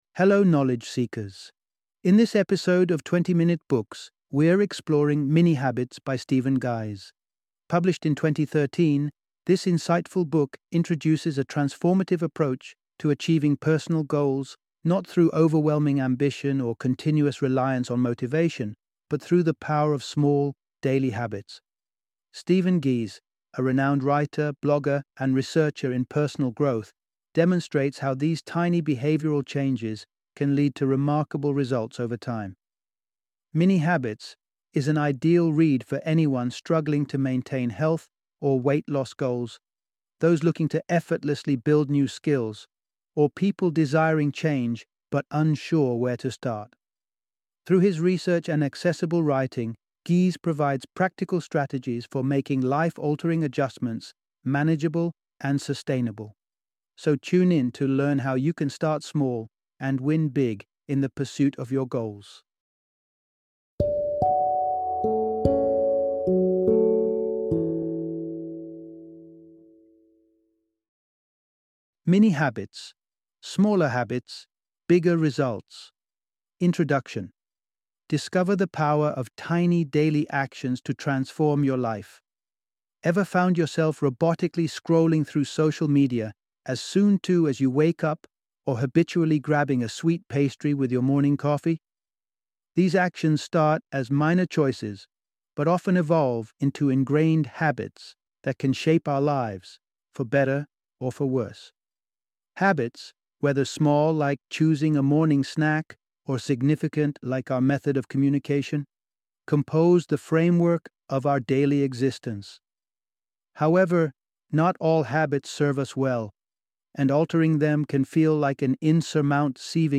Mini Habits - Audiobook Summary